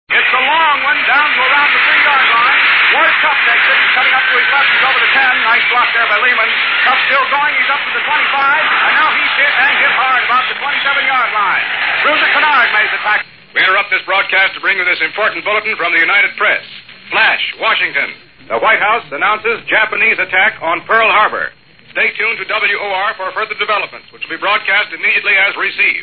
FDR Declaration of War Speach, Dec 8, 1941
declaration_of_war.mp3